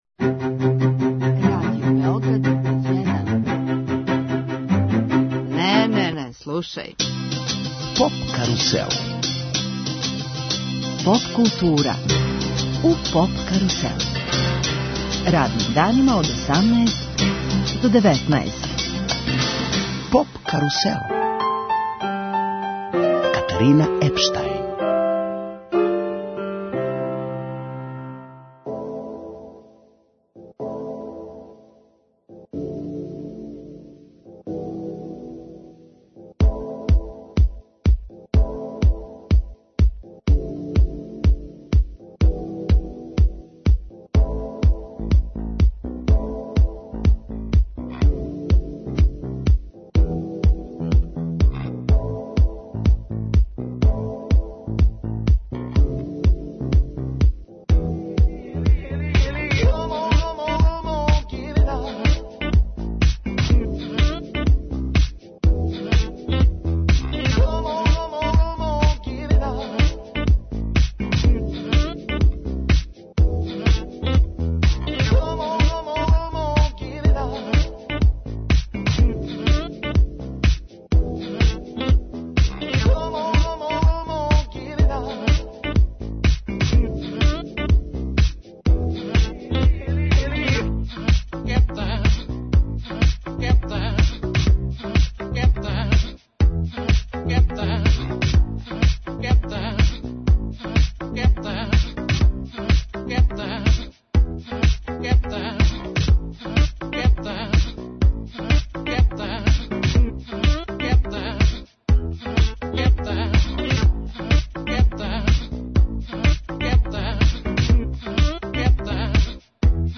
Двосатна специјална емисија посвећена је „Love фест-у“. Уживо, из Врњачке Бање, представићемо други по величини музички фестивал у Србији.